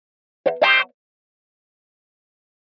Guitar Thin Wah-Wah Chord - Very Short